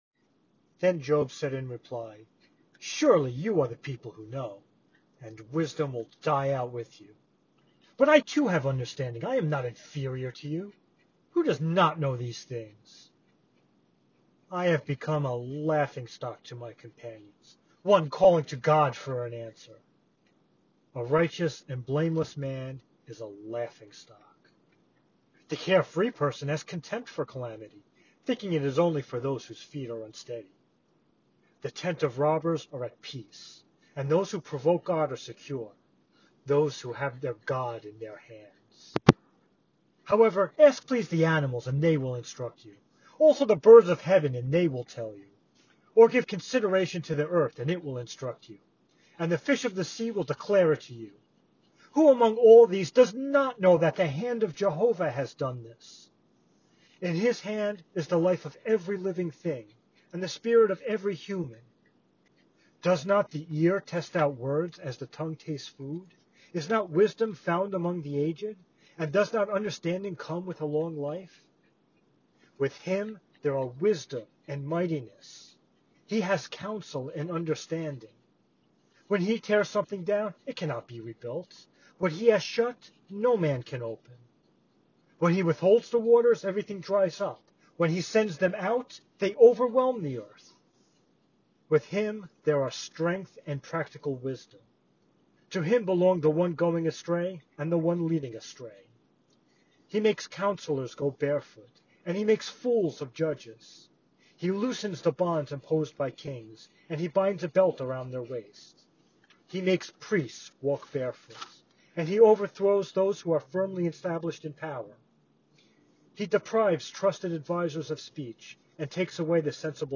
Audio – Bible Reading – Job chapter 12 – RVFFC Corp
Job-Bible-Reading.m4a